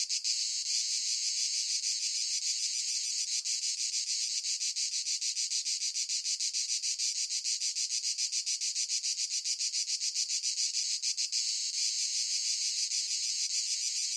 crickets_1.ogg